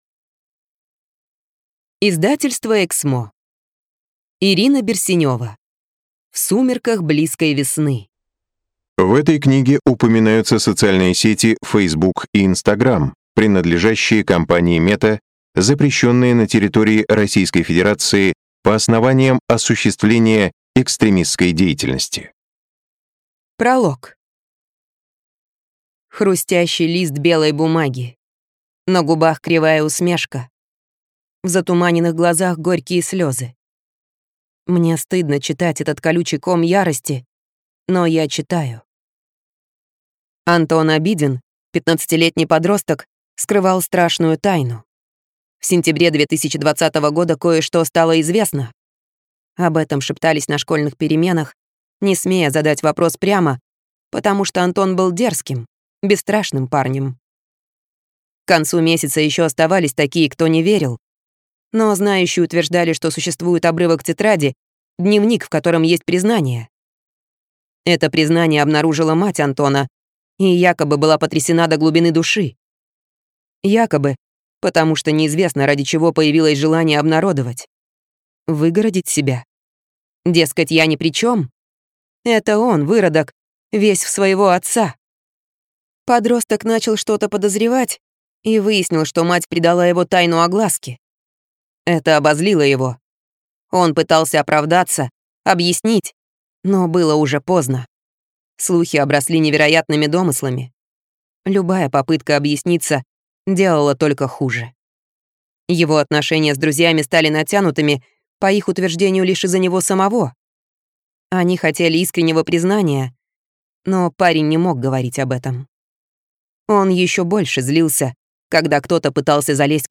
Аудиокнига В сумерках близкой весны | Библиотека аудиокниг